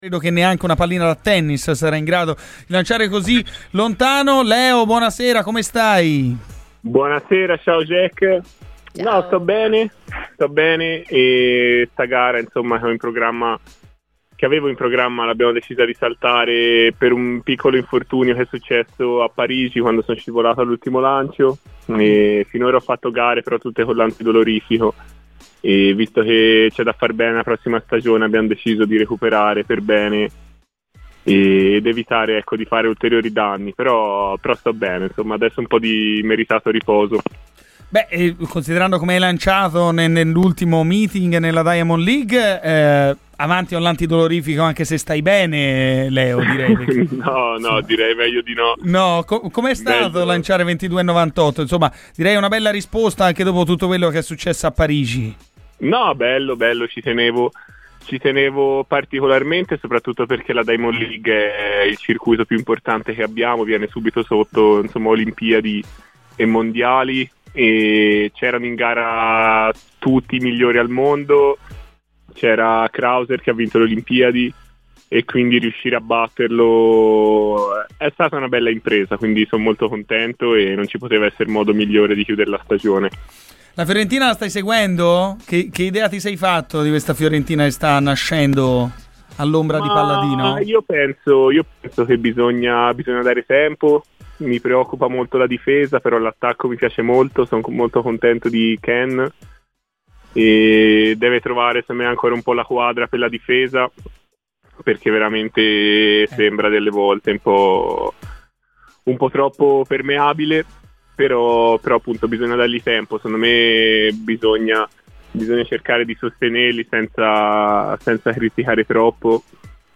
Il pesista di Bagno a Ripoli, Leonardi Fabbri, grande tifoso della Fiorentina, è intervenuto a Radio FirenzeViola durante "Garrisca al Vento" sia per parlare dell'attualità viola che per spiegare come sta: "Sto bene ed ora mi godo un po' di riposo.